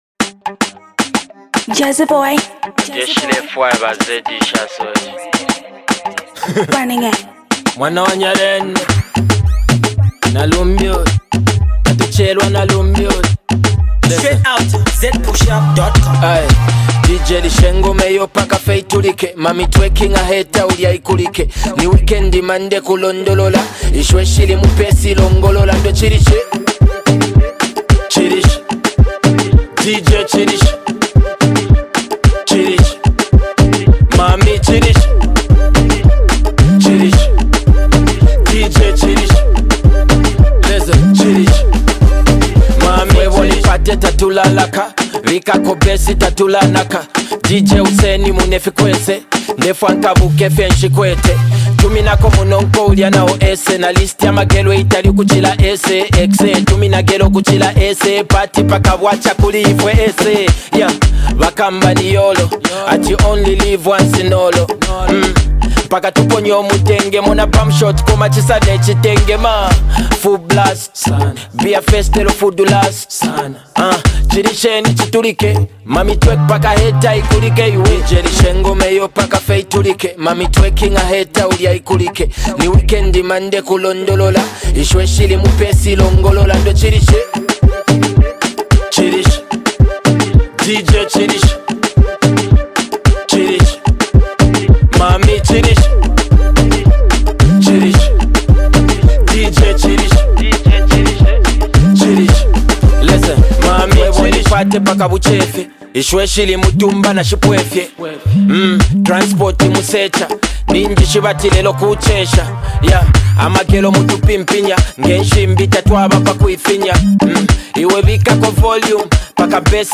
Brand new dance-hall tune